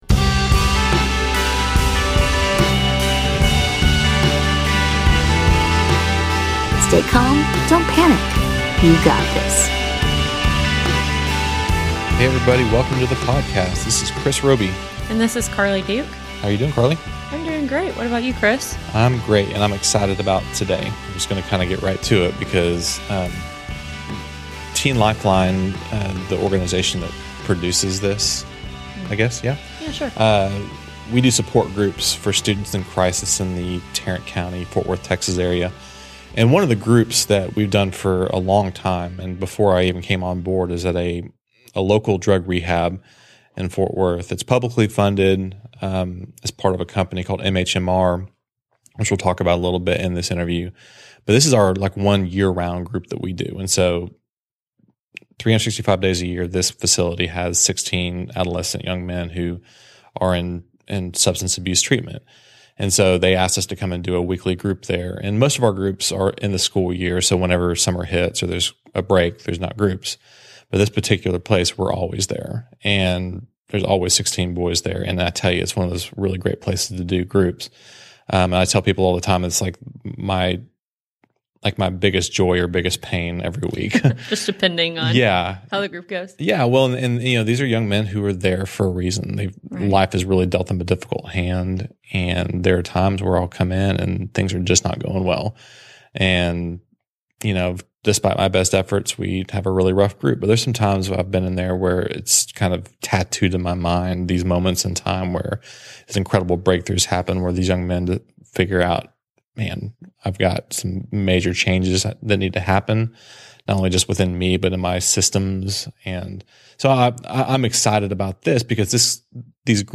by Teen Life | Nov 1, 2016 | Mental Health, Parenting, Podcast